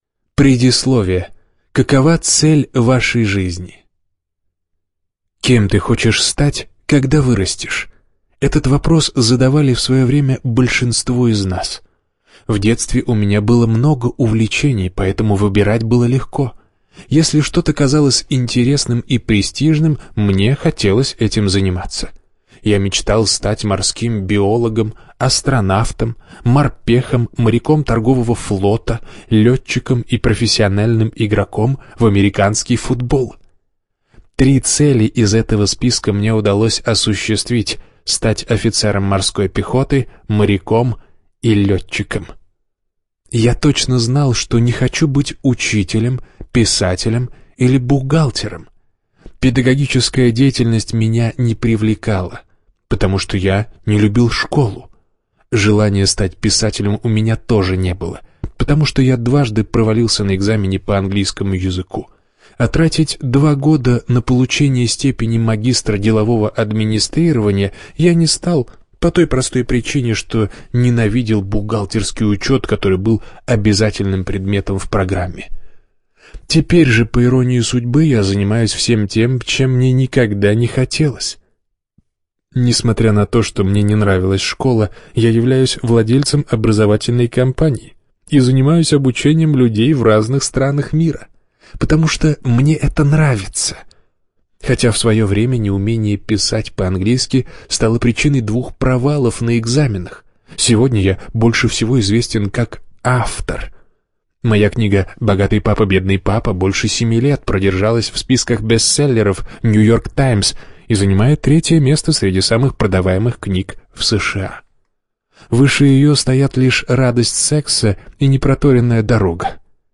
Аудиокнига Квадрант денежного потока | Библиотека аудиокниг